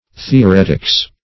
Theoretics \The`o*ret"ics\, n.